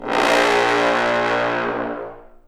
Index of /90_sSampleCDs/Roland L-CD702/VOL-2/BRS_Bs.Trombones/BRS_Bs.Bone Sect